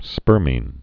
(spûrmēn)